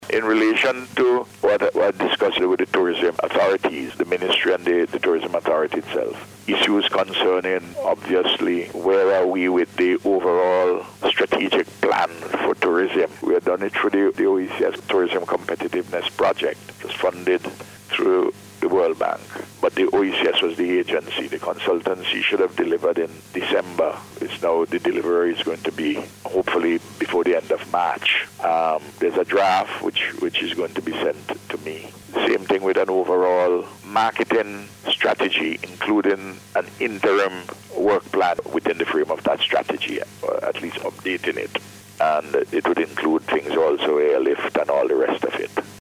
Dr. Gonsalves made the statement while speaking on NBC Radio earlier this week.